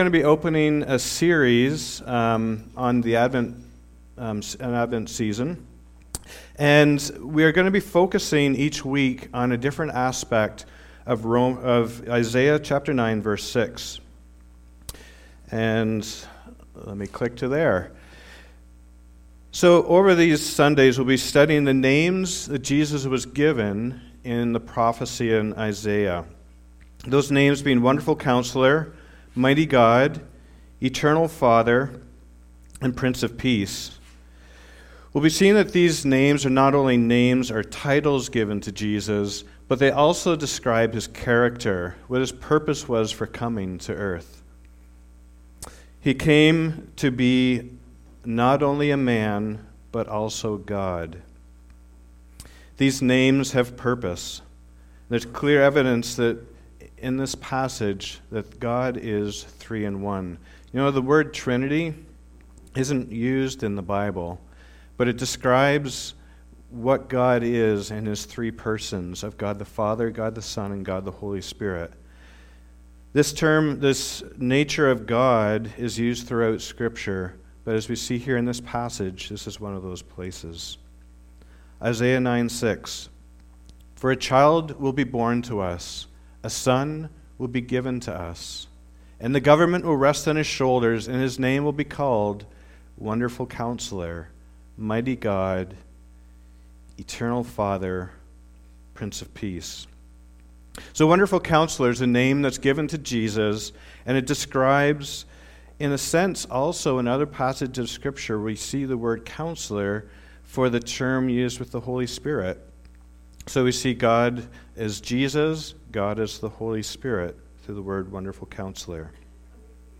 SERMONS - Community Bible Church